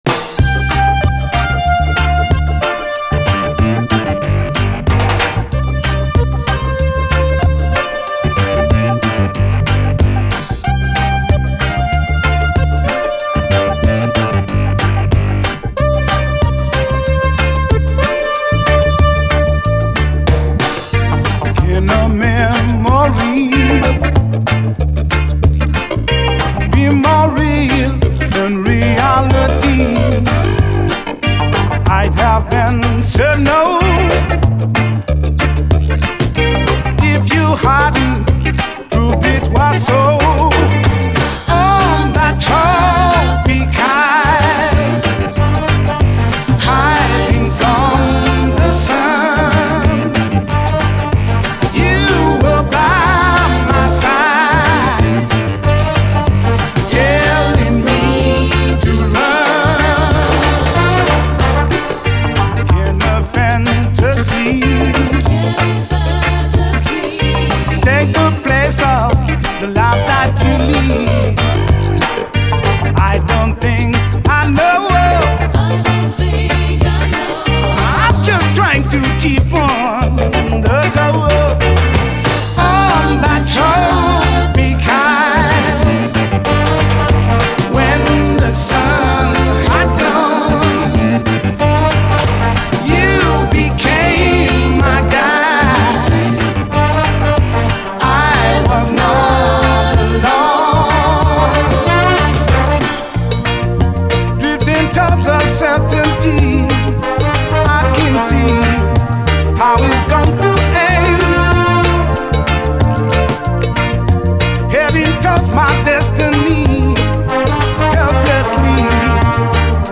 REGGAE, 7INCH
フランス産80’Sシンセ・ディスコ・レゲエ！